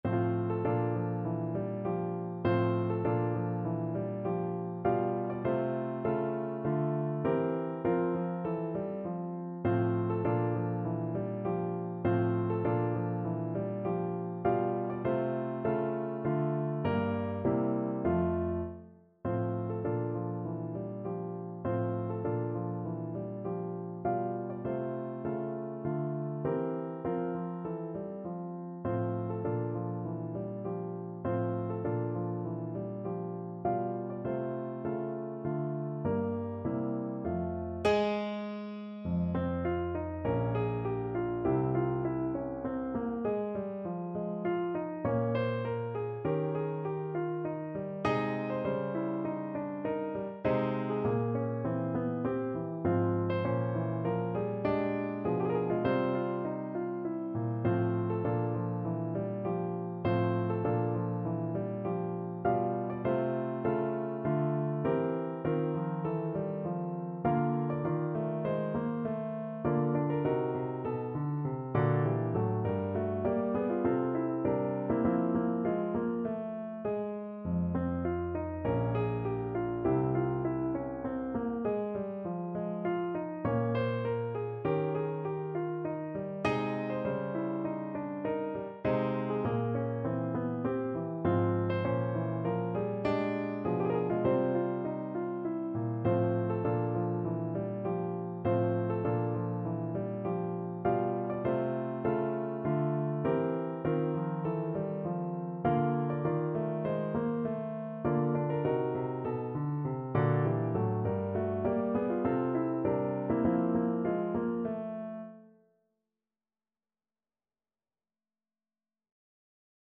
No parts available for this pieces as it is for solo piano.
4/4 (View more 4/4 Music)
Piano  (View more Advanced Piano Music)
Classical (View more Classical Piano Music)